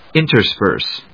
in・ter・sperse /ìnṭɚspˈɚːs‐təspˈəːs/